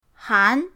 han2.mp3